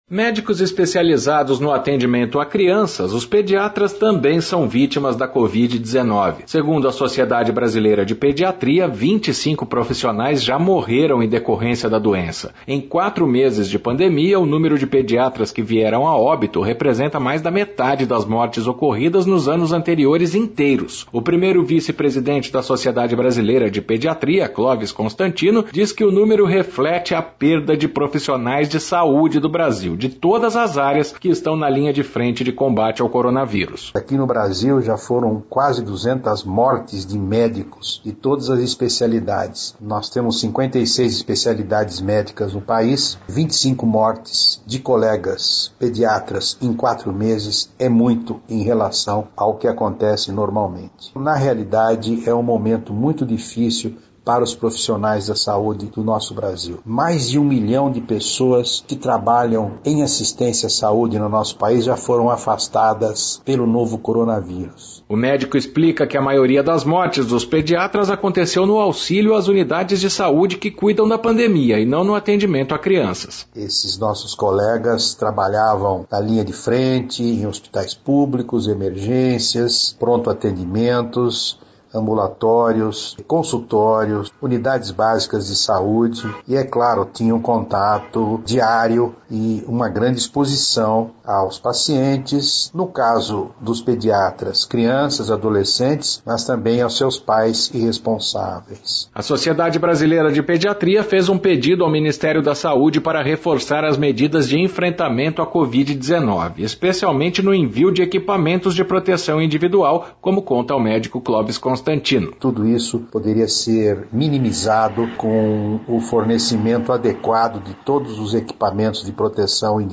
O médico explica que a maioria das mortes dos pediatras aconteceu no auxílio às unidades de saúde que cuidam da pandemia e não no atendimento a crianças.